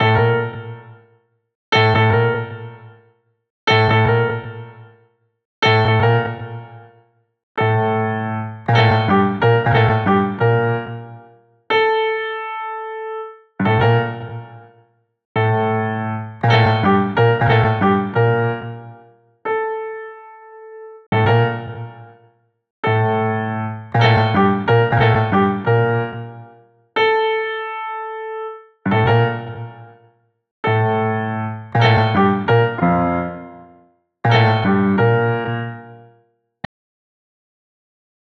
piano 01 [2025-11-11..> 2025-11-16 12:42   21M